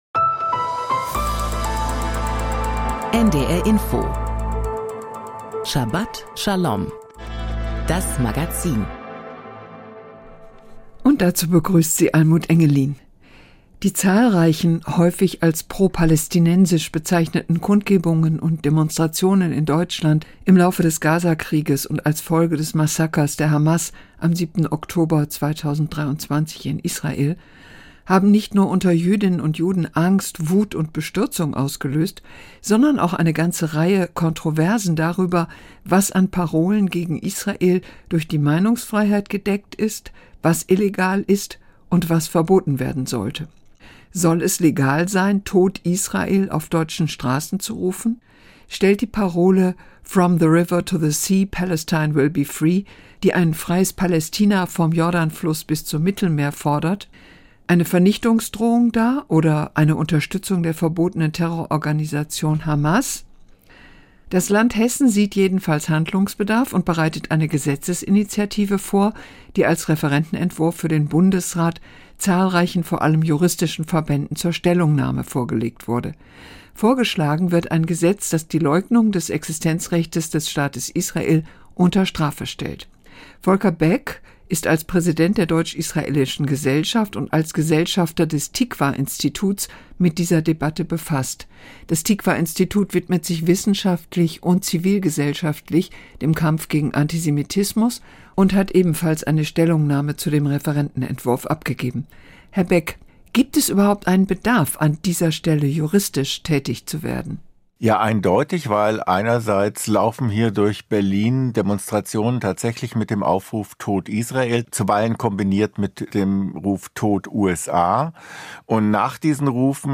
Interview mit Volker Beck